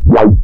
tekTTE63017acid-A.wav